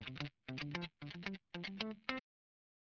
GUITAR LOOPS - PAGE 1 2 3 4